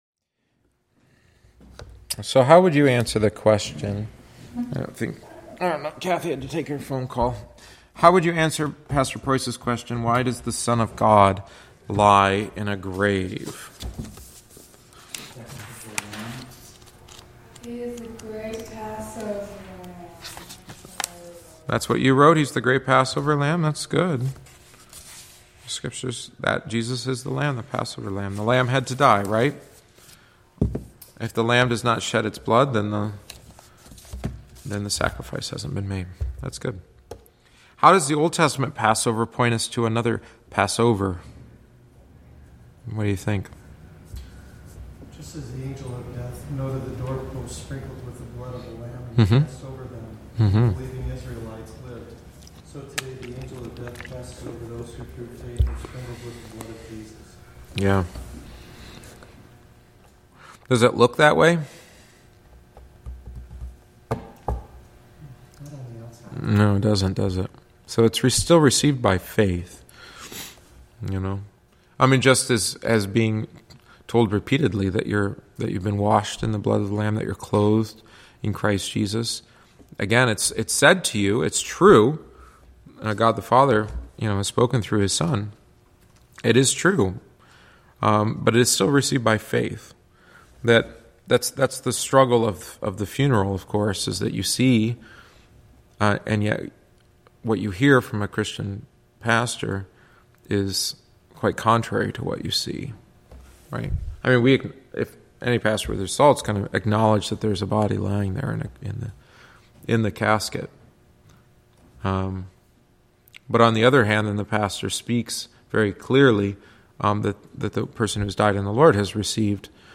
The attached audio omits the text of the book and includes only our discussion.